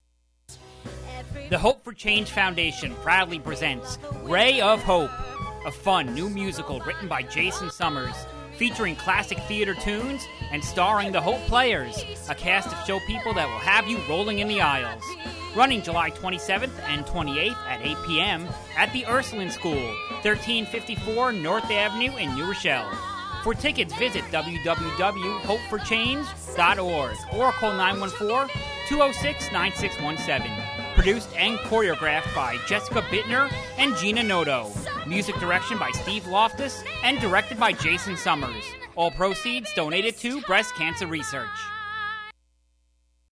Ray of Hope Radio Commercial